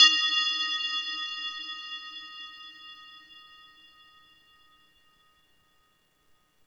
drum-hitfinish.wav